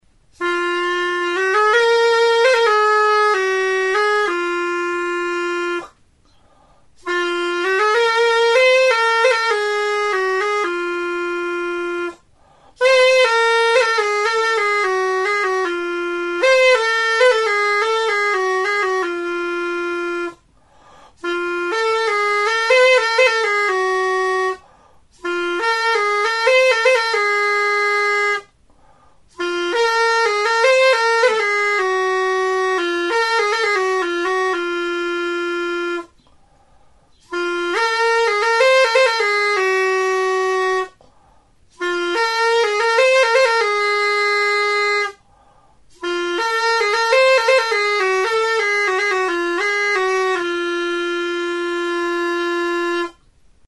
Aerophones -> Reeds -> Single fixed (clarinet)
Recorded with this music instrument.
Alboka moduko mihia duen kanabera hoditxo bat da.